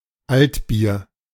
Altbier (German: [ˈaltˌbiːɐ̯]